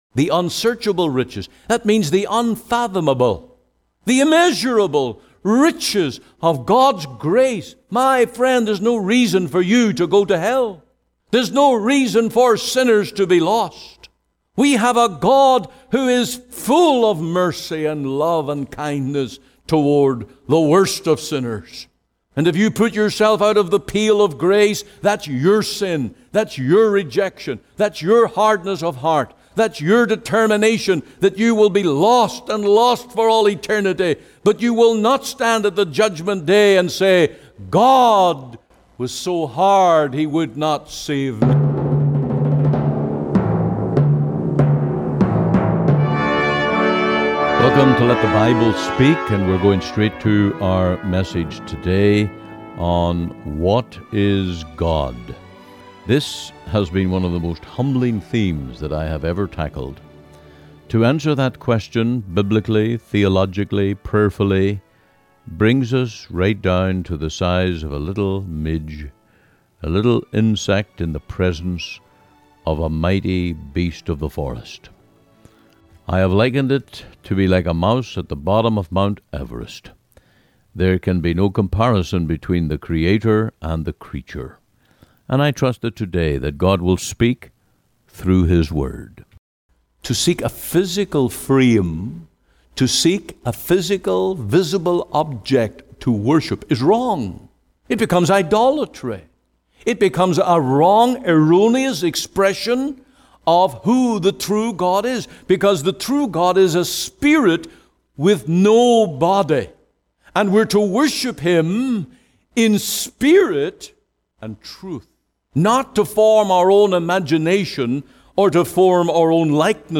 Sermons | The Free Presbyterian Church in Cloverdale